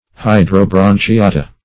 Definition of hydrobranchiata.
Search Result for " hydrobranchiata" : The Collaborative International Dictionary of English v.0.48: Hydrobranchiata \Hy`dro*bran`chi*a"ta\, n. pl.